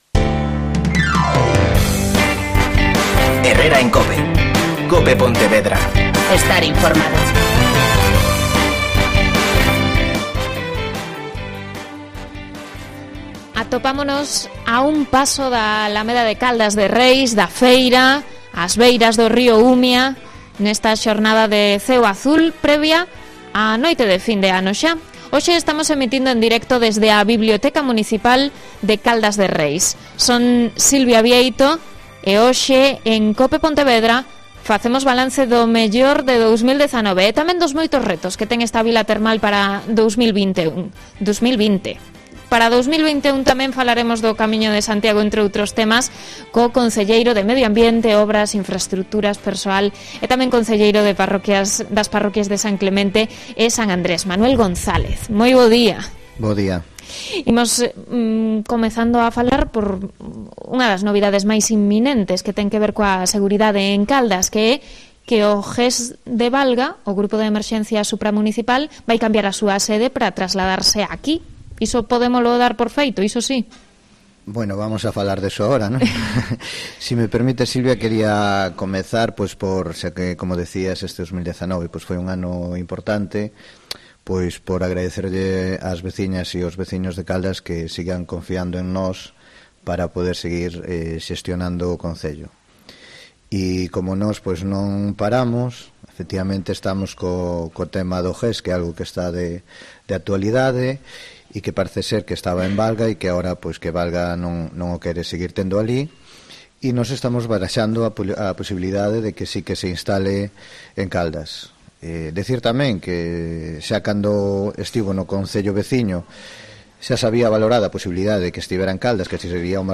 AUDIO: Especial Programación desde Caldas de Reis.